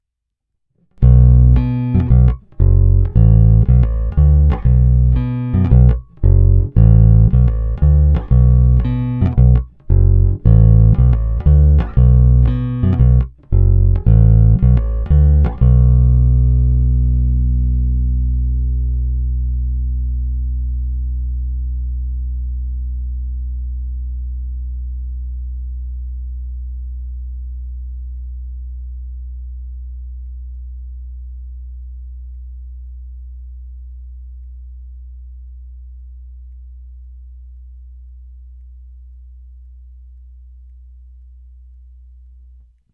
Neskutečně pevný, zvonivý, s těmi správnými středy, co tmelí kapelní zvuk, ale při kterých se basa i prosadí.
Není-li uvedeno jinak, následující nahrávky jsou provedeny rovnou do zvukové karty, jen normalizovány, jinak ponechány bez úprav.
Hra u krku